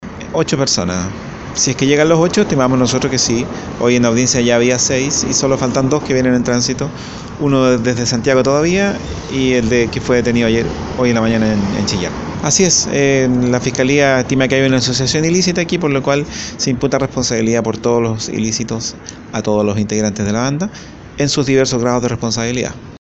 El Fiscal Álvaro Pérez, de la Fiscalía de Análisis Criminal y Focos Investigativos de la Región de Los Ríos, explicó que el Ministerio Público estima que hay una asociación ilícita para delinquir, por lo cual se les formalizará a las ocho personas por los mismos delitos, con distintos grados de participación.